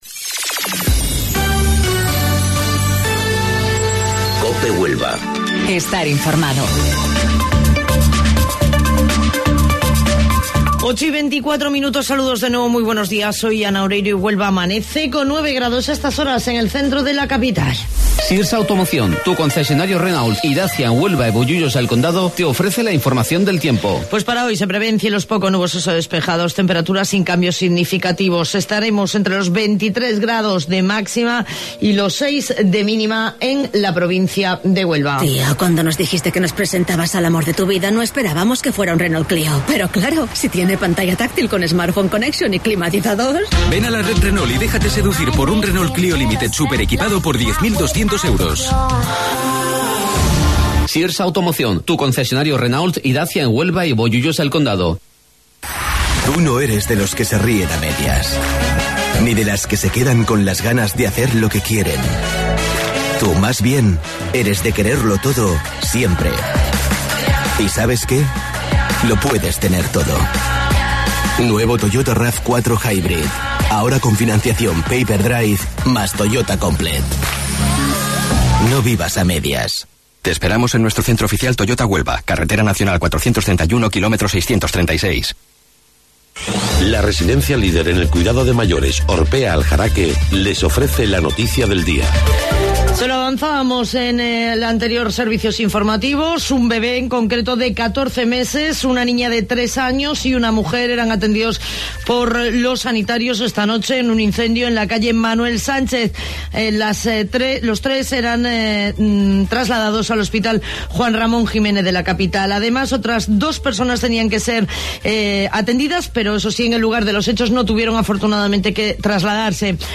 AUDIO: Informativo Local 08:25 del 22 de Marzo